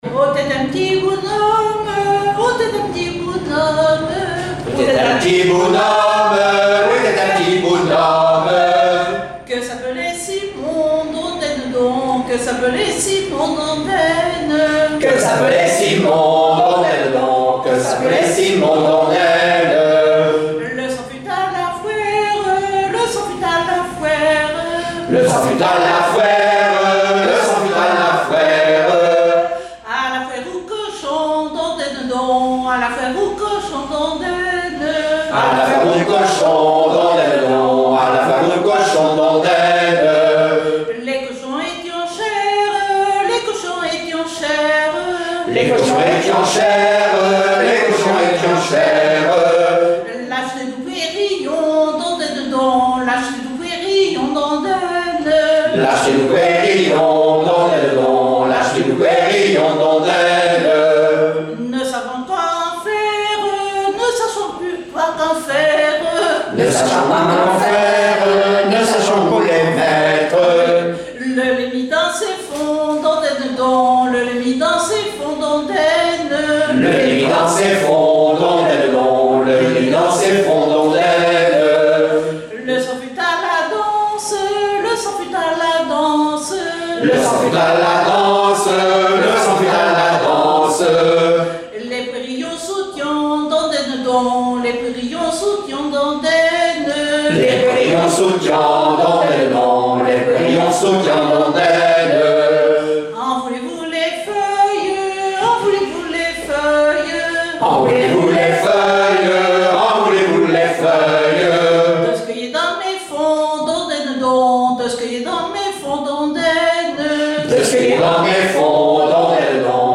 danse : ronde : rond de l'Île d'Yeu
Genre laisse
répertoire de chansons populaires islaises
Pièce musicale inédite